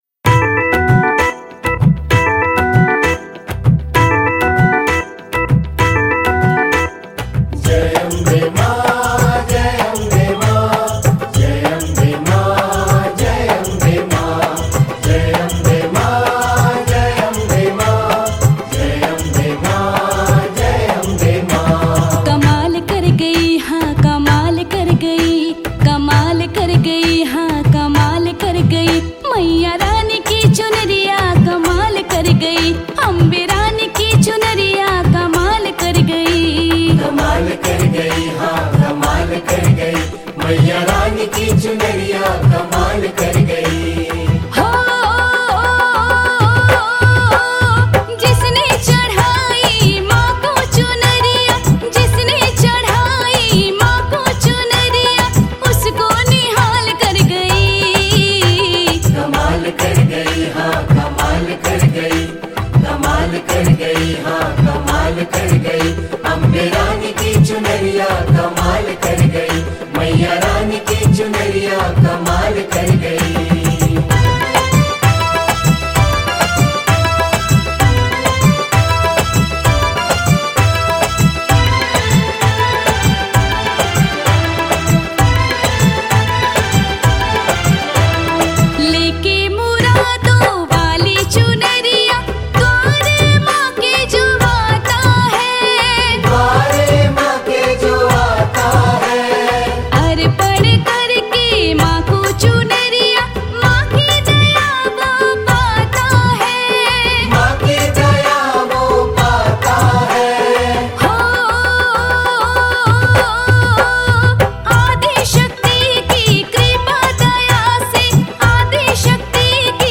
Bhakti Hindi Bhajan